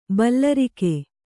♪ ballarike